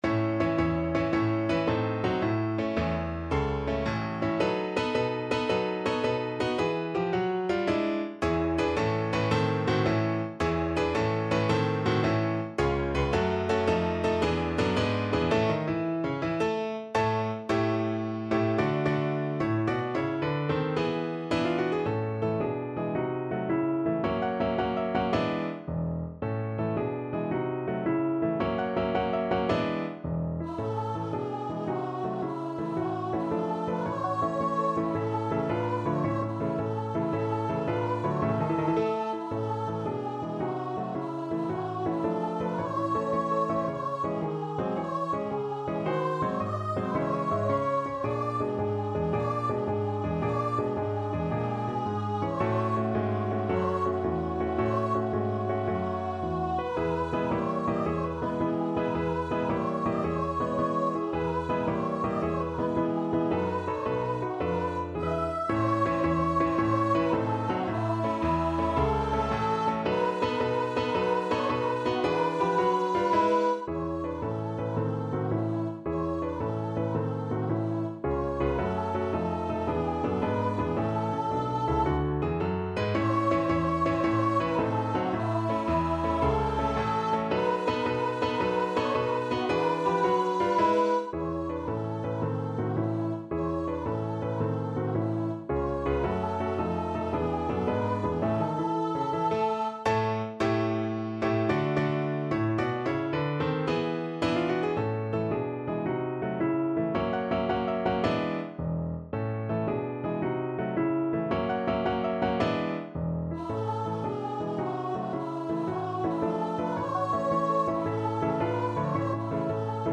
Marziale .=110
6/8 (View more 6/8 Music)
E5-E6
Traditional (View more Traditional Voice Music)